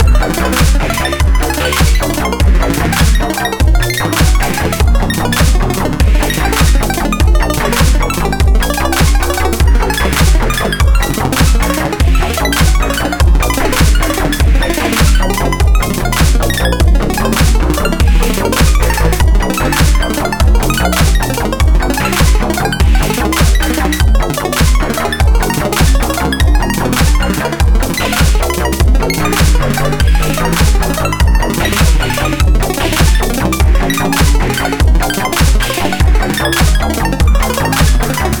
100 BPM